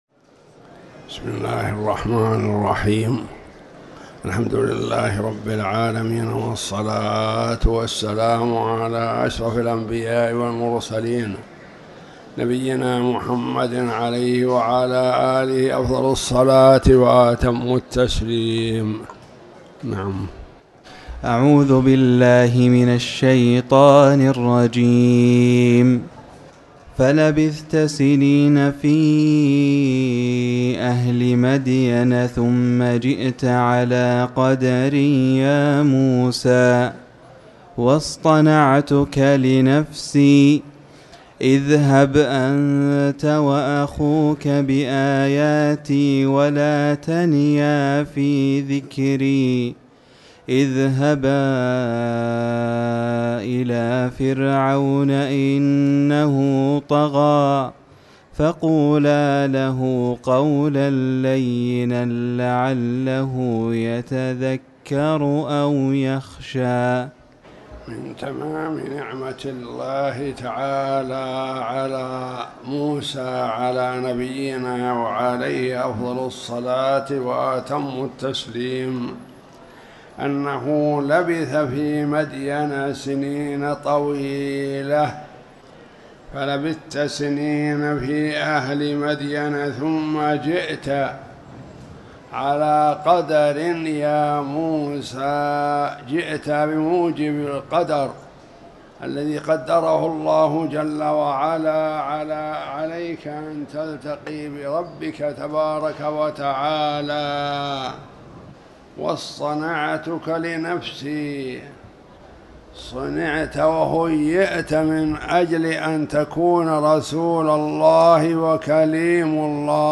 تاريخ النشر ٧ صفر ١٤٤٠ هـ المكان: المسجد الحرام الشيخ